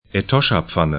Pronunciation
Etoschapfanne e'tɔʃa-pfanə Etosha Pan i'tɔʃə 'pæn en Gebiet / region 18°45'S, 16°15'E